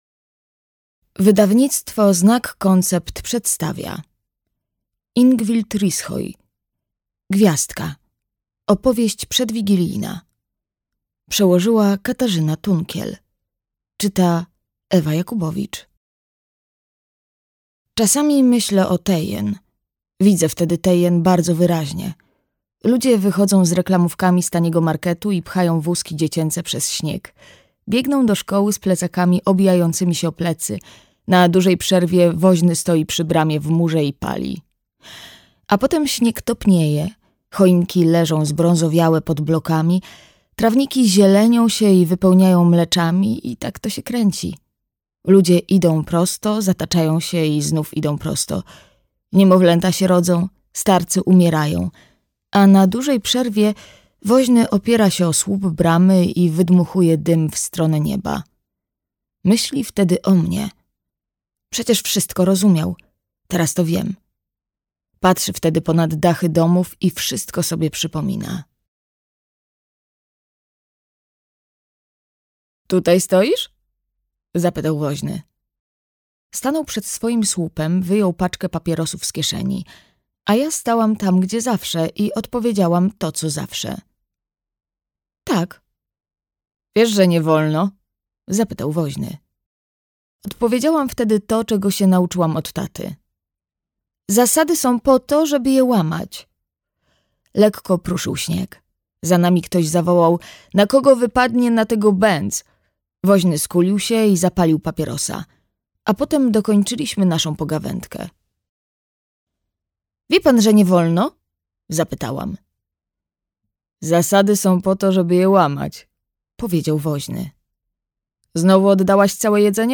Gwiazdka. Opowieść przedwigilijna - Rishoi Ingvild - audiobook + książka